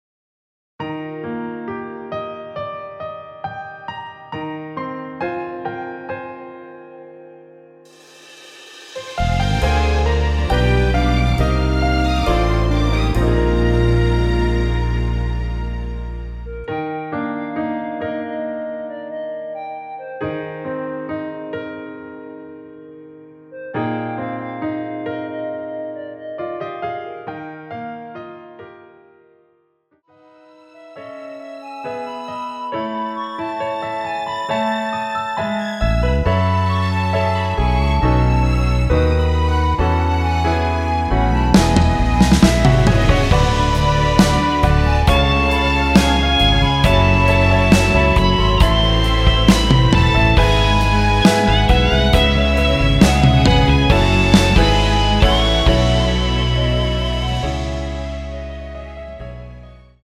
원키에서(+4)올린 멜로디 포함된 MR입니다.
여성분이 부르실수 있는키로 제작 하였습니다.(미리듣기 참조)
Eb
앞부분30초, 뒷부분30초씩 편집해서 올려 드리고 있습니다.